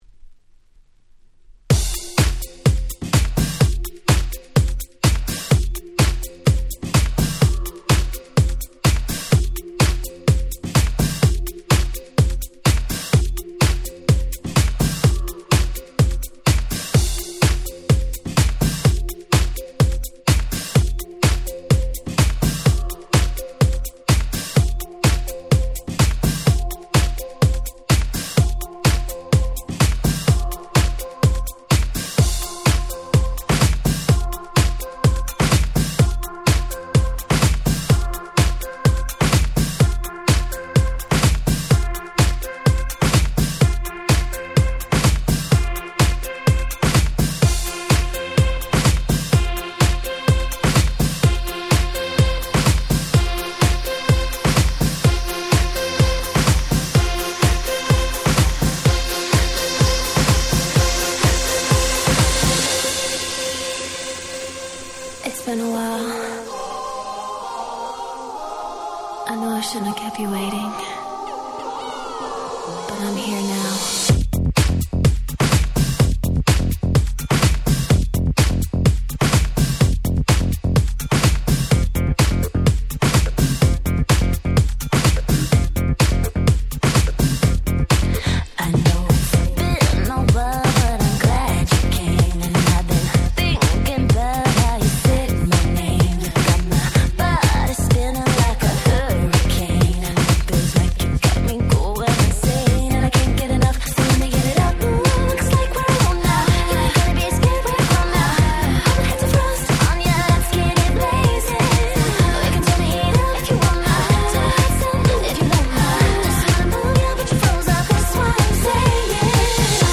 08' Smash Hit R&B / Vocal House / EDM / Pops !!